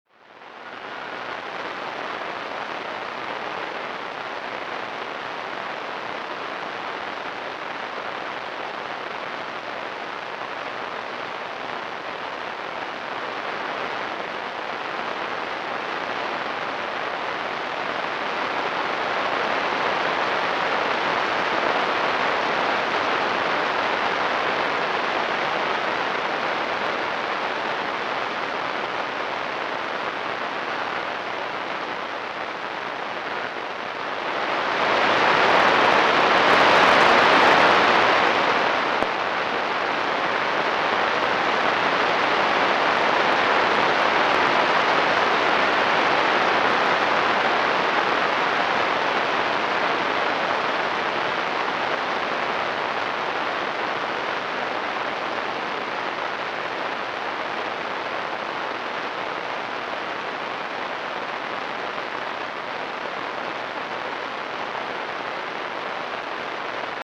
Significant radio emissions included as they happen.
Interesting 28 MHz radio burst at 1858 UT. 1 MB